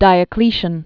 (dīə-klēshən) Originally Gaius Aurelius Valerius Diocletianus.